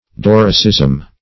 Doricism \Dor"i*cism\, n.
doricism.mp3